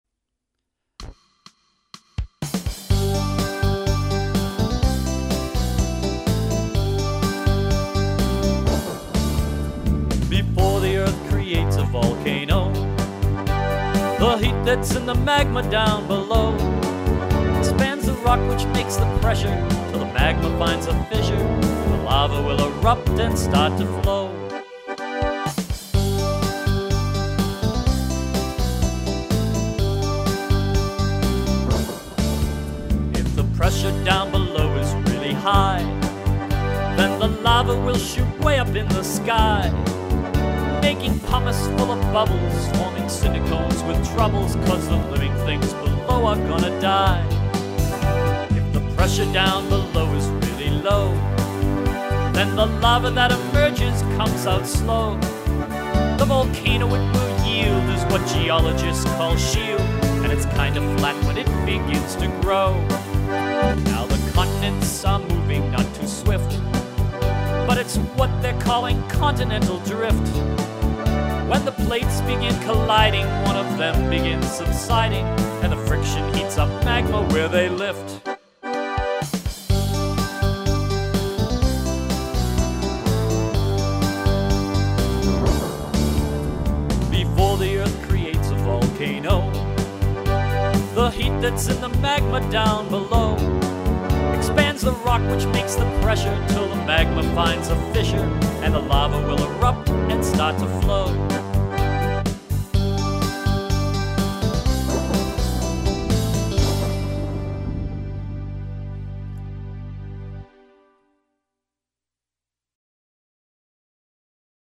These articulate words and catchy tunes can teach